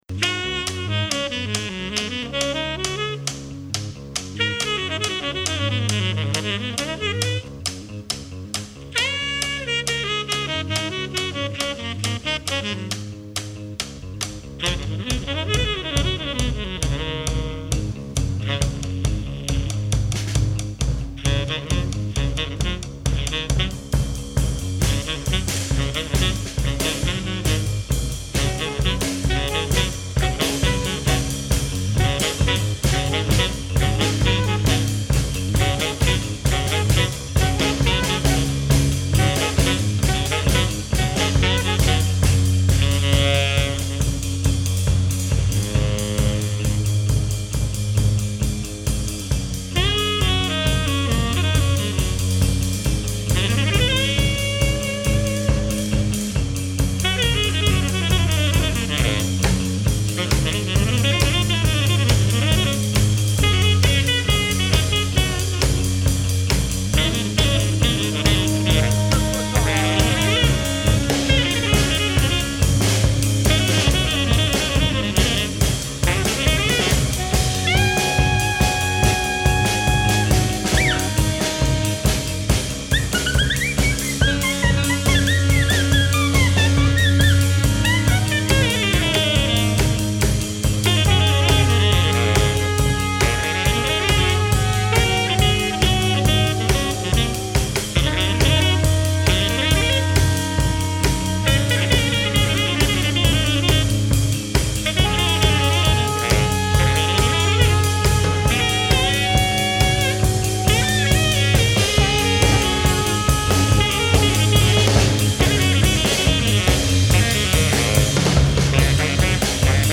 Recorded live at the 39th Street loft in Brooklyn.
drums
bass
alto saxophone, fx
tenor saxophone
Stereo (Metric Halo / Pro Tools)